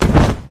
enemy_falls.ogg